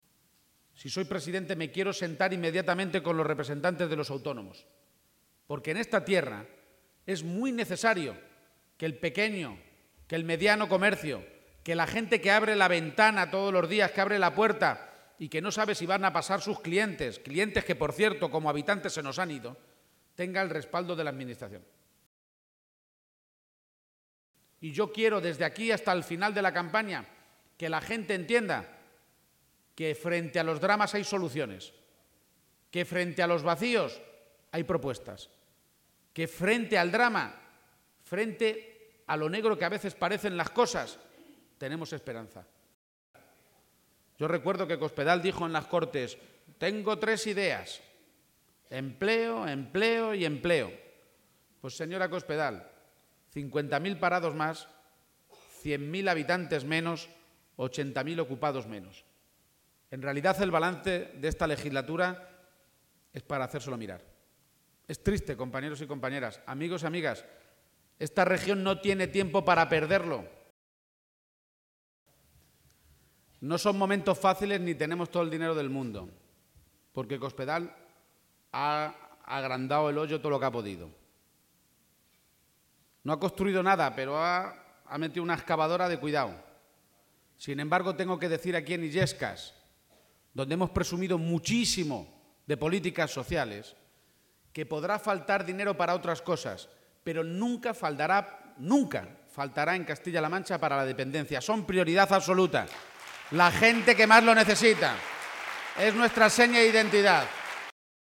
Lo ha hecho en la localidad toledana de Illescas, donde ha cerrado la jornada de este último domingo de campaña que había iniciado con un gran acto público junto a la Presidenta de Andalucía, Susana Díaz, en Puertollano.
Audios García-Page en Illescas